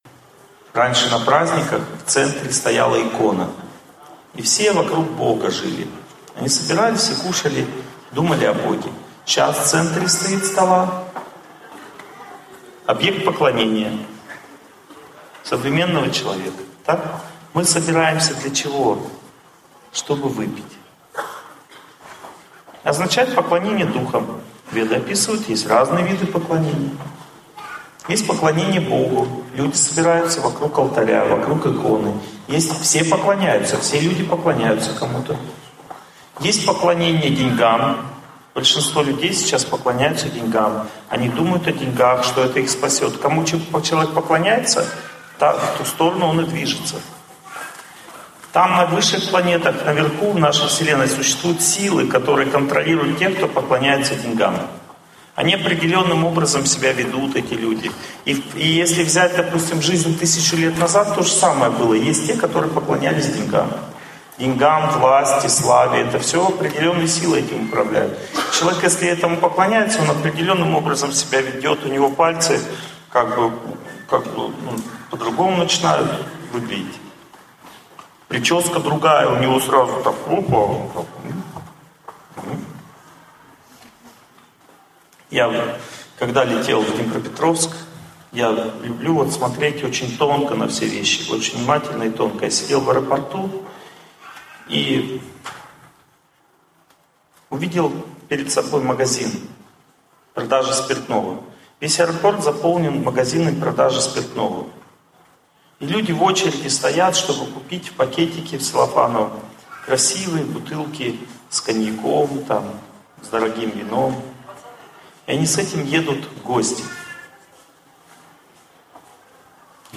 Аудиокнига Питание в благости | Библиотека аудиокниг